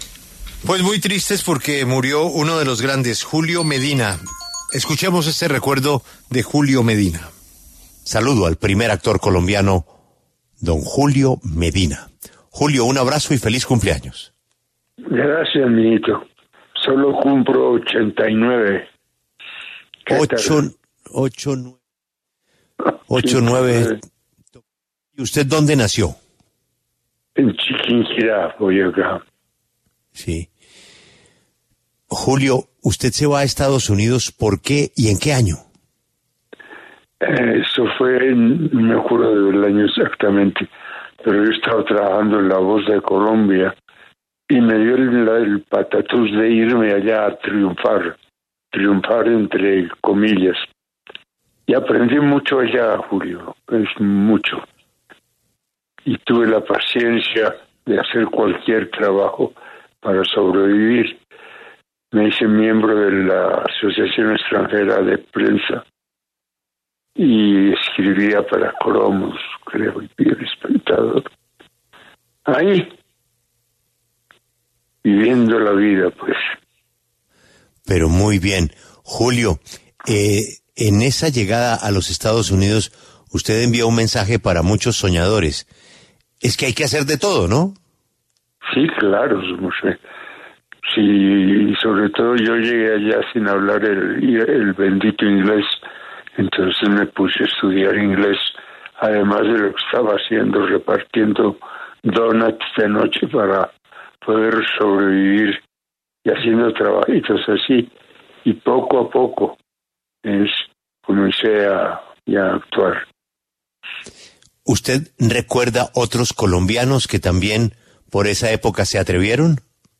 Reviva la entrevista en La W con el actor Julio Medina Salazar